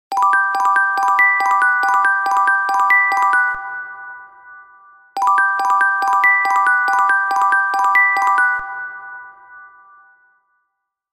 Звуки телефона TCL
Мелодия будильника Омега